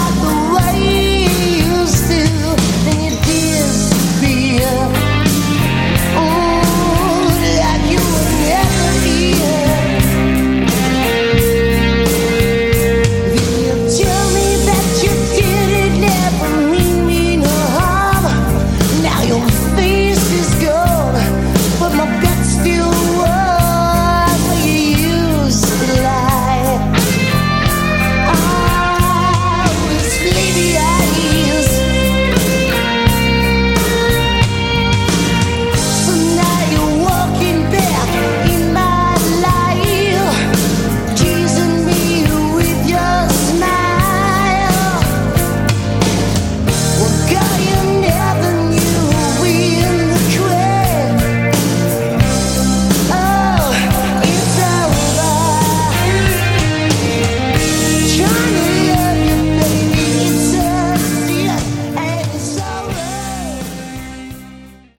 Category: Hard Rock
lead vocals
guitars
bass
drums
All songs recorded 1989-90.
Production, you gotta hand it to 'em, it sounds great.